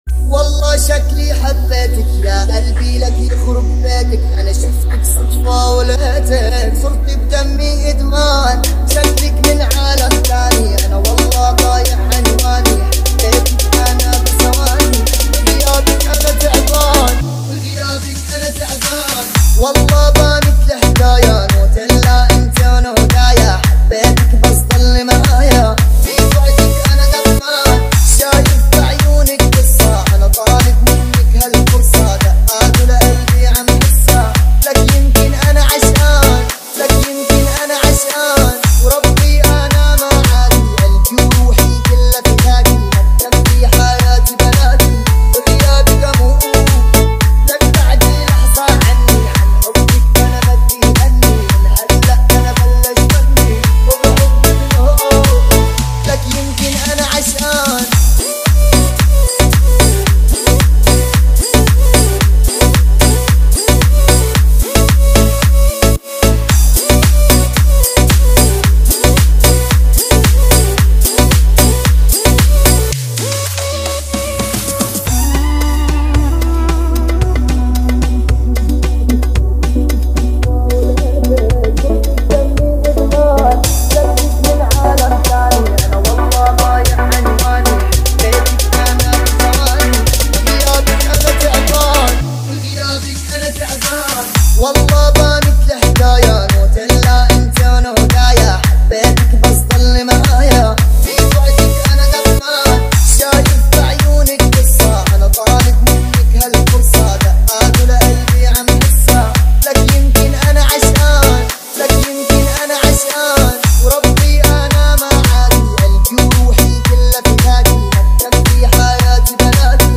ریمیکس بیس دار تند باحال